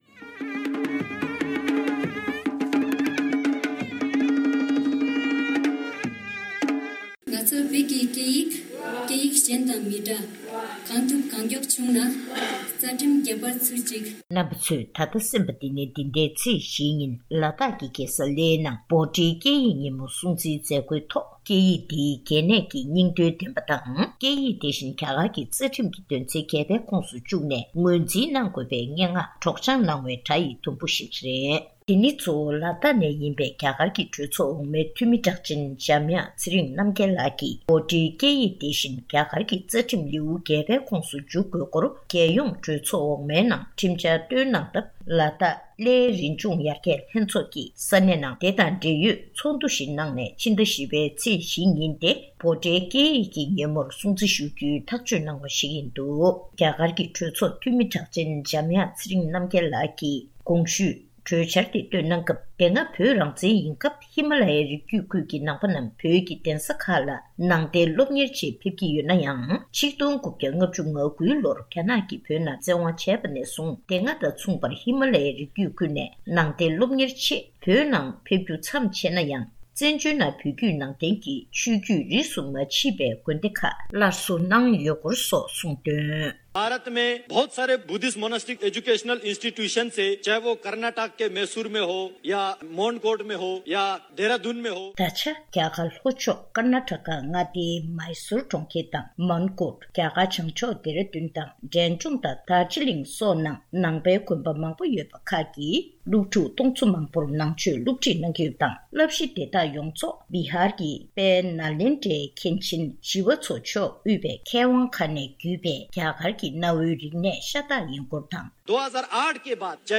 འབྲེལ་ཡོད་མི་སྣར་གནས་འདྲི་ཞུས་ཏེ་ཕྱོགས་སྒྲིག་དང་སྙན་སྒྲོན་ཞུས་པར་གསན་རོགས་ཞུ།།